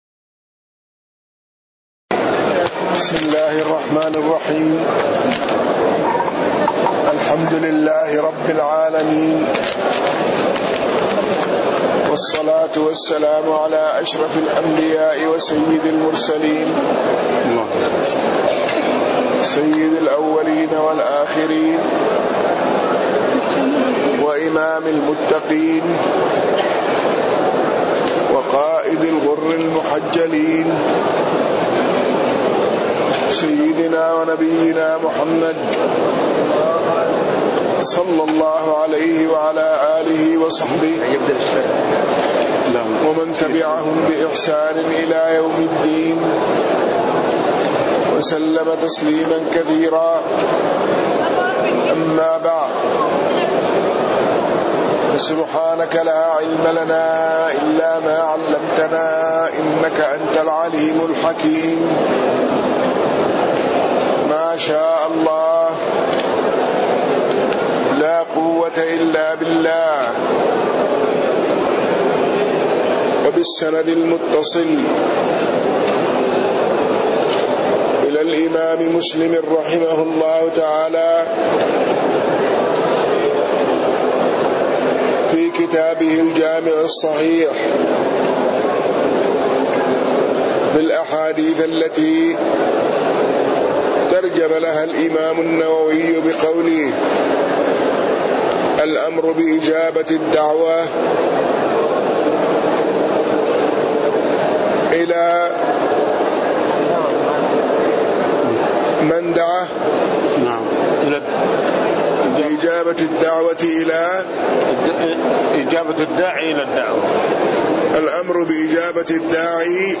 تاريخ النشر ١٩ جمادى الآخرة ١٤٣٤ هـ المكان: المسجد النبوي الشيخ